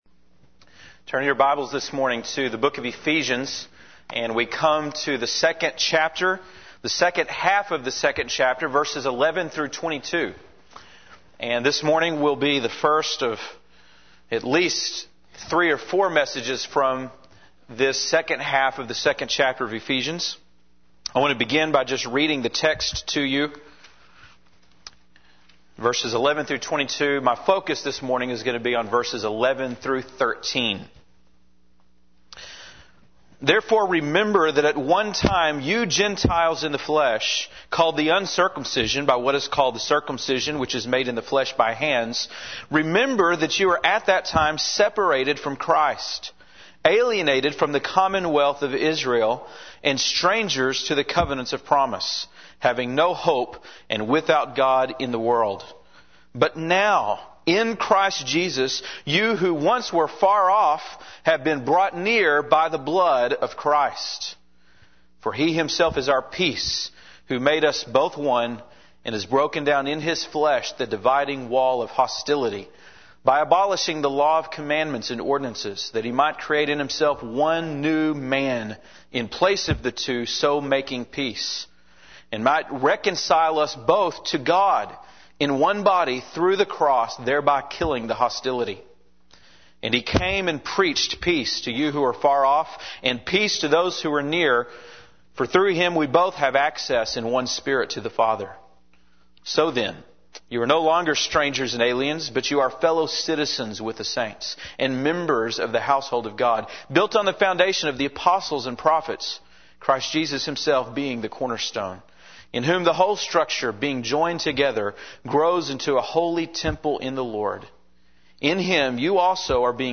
February 6, 2005 (Sunday Morning)